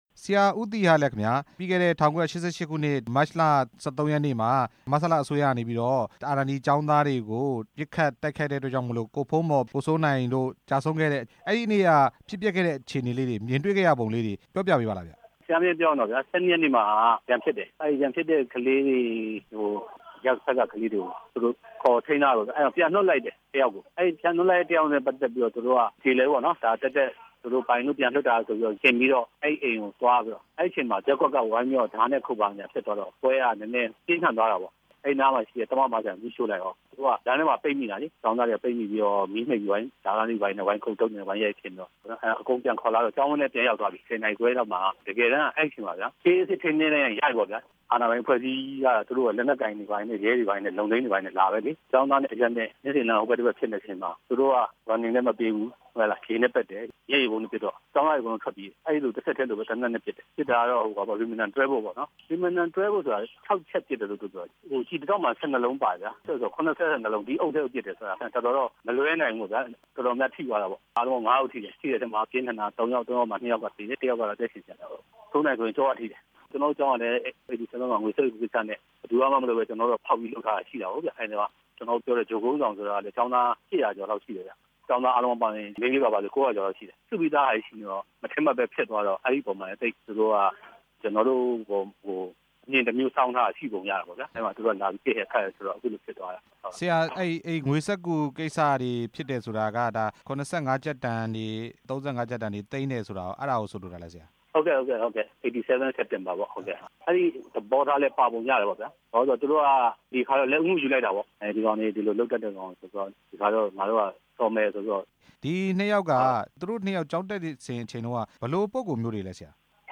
ကိုဖုန်းမော်နဲ့ ကိုစိုးနိုင်တို့ သေဆုံးခဲ့ရတဲ့အကြောင်း ကျောင်းဆရာတစ်ဦးနဲ့ မေးမြန်းချက်